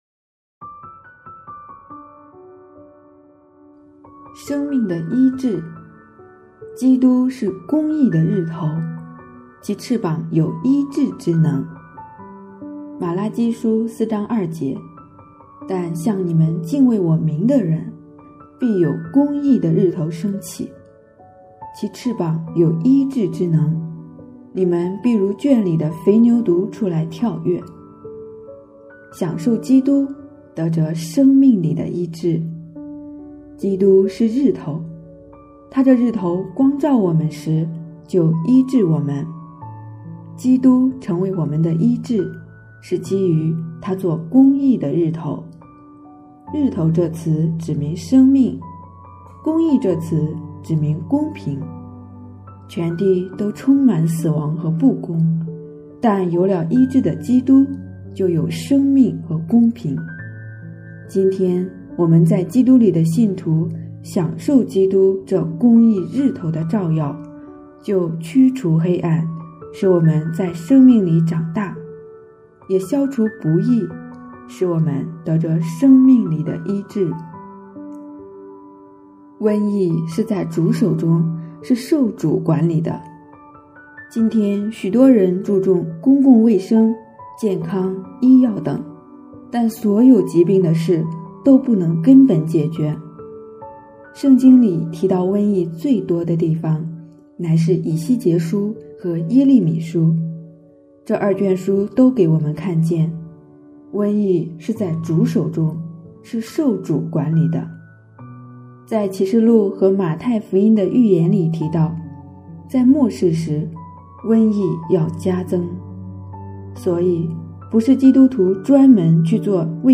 有声版